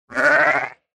Звуки овечки
8 Баран бееее